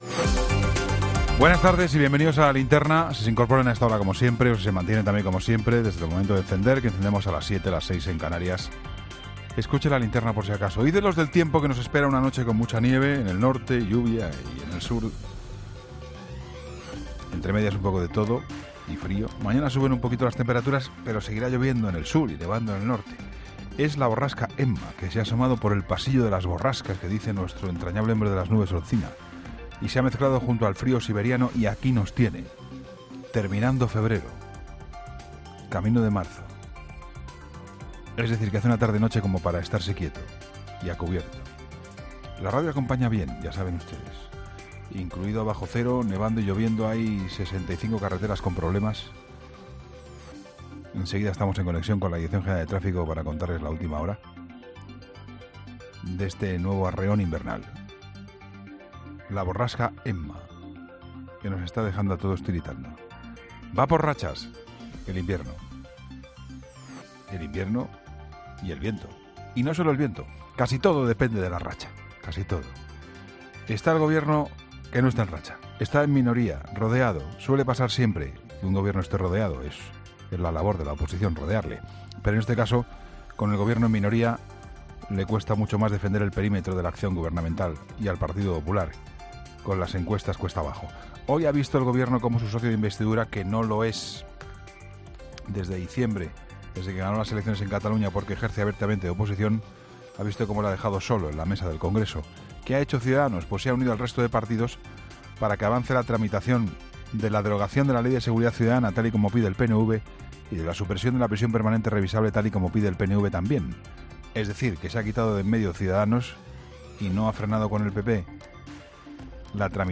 EN 'LA LINTERNA'